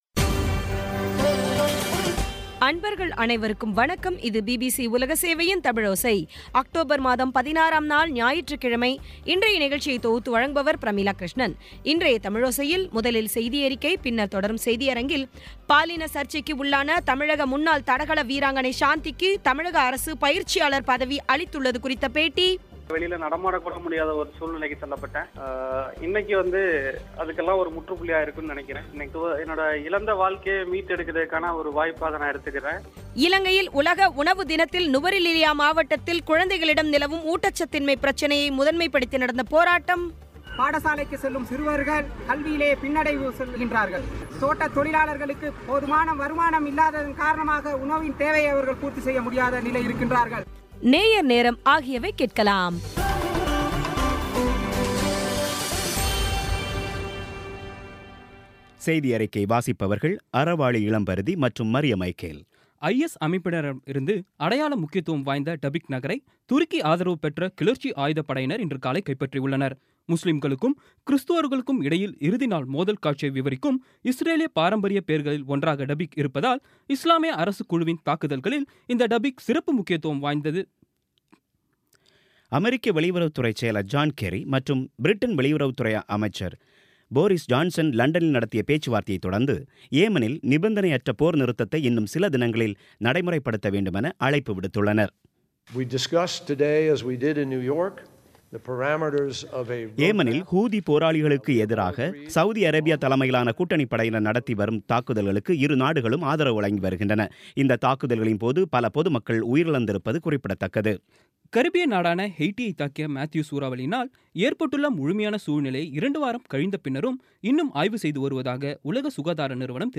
இன்றைய தமிழோசையில், முதலில் செய்தியறிக்கை, பின்னர் தொடரும் செய்தியரங்கத்தில், பாலின சர்ச்சைக்கு உள்ளான தமிழக முன்னாள் தடகள வீராங்கனை சாந்திக்கு தமிழக அரசு பயிற்சியாளர் பதவி அளித்துள்ளது குறித்த பேட்டி..